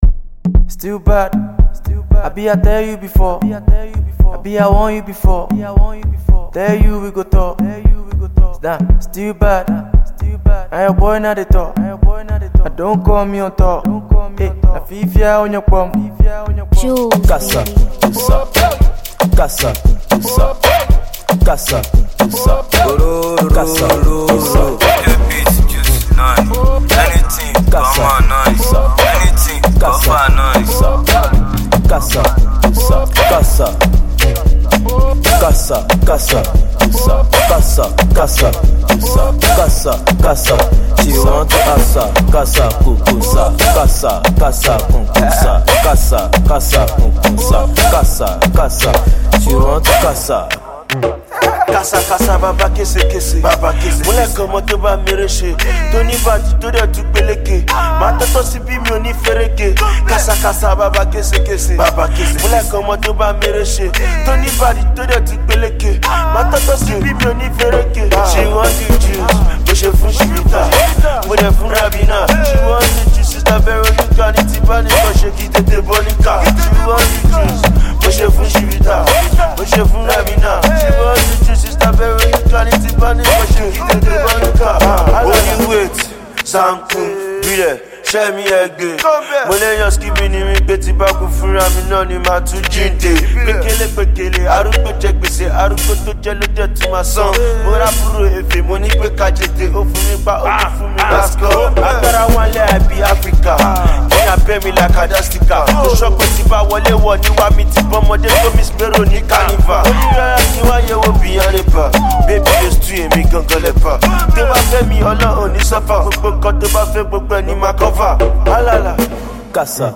The song has heavy pop and Ghanaian highlife influences